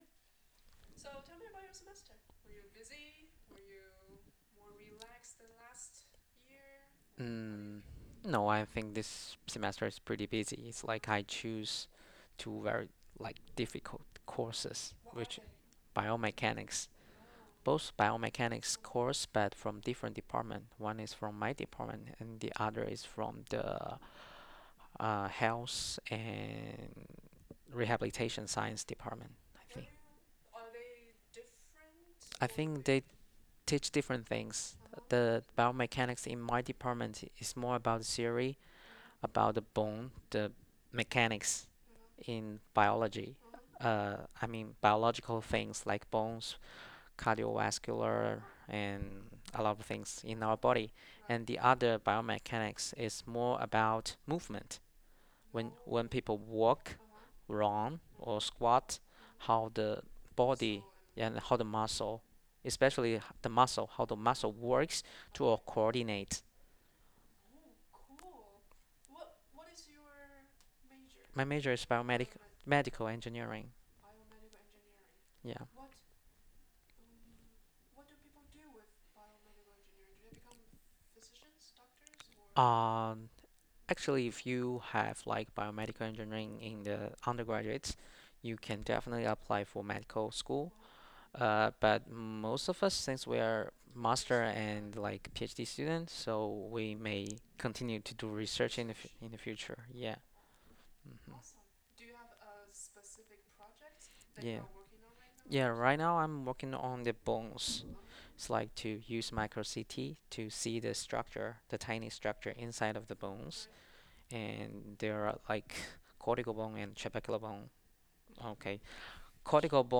This task aimed to elicit spontaneous speech from non-native speakers of English. ITAs were asked to answer questions related to their everyday life, teaching, and research work in a semi-structured interview format. Each session lasted for at least five minutes and included speech by the interviewer, who was a graduate research assistant.
Participant 12 Spontaneous speech Baseline 3